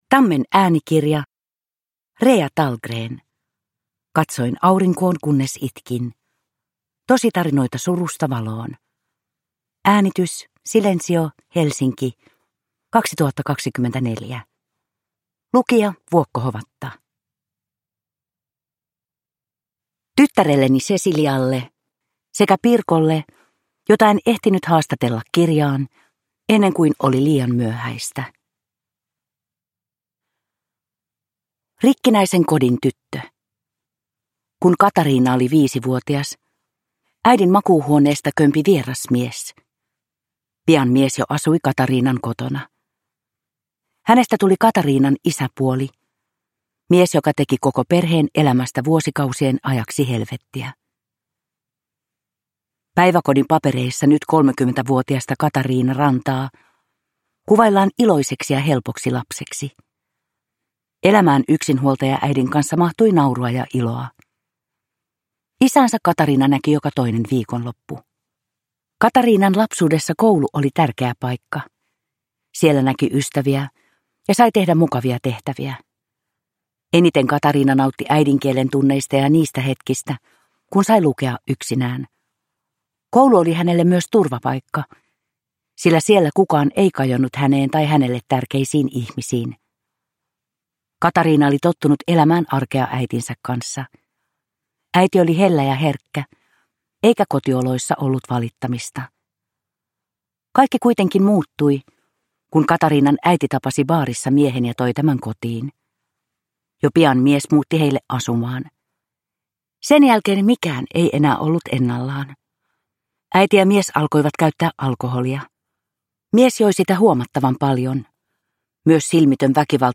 Katsoin aurinkoon kunnes itkin – Ljudbok – Digibok